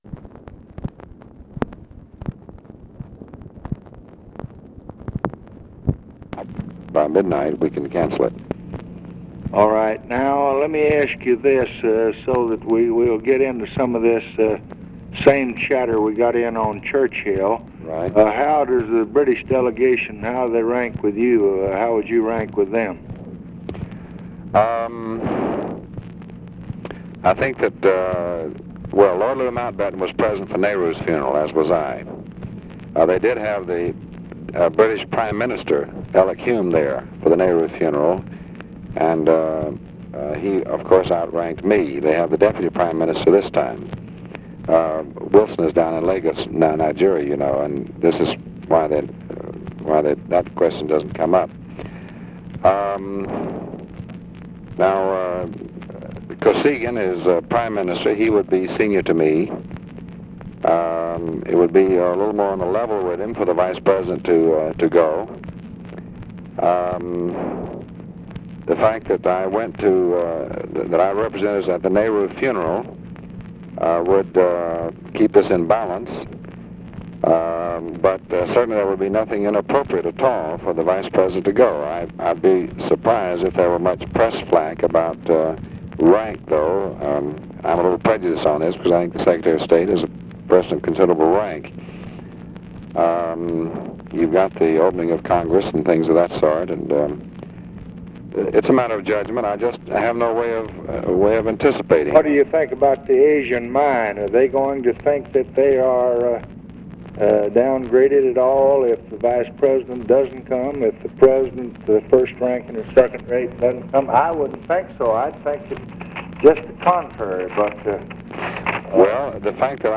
Conversation with DEAN RUSK, January 11, 1966
Secret White House Tapes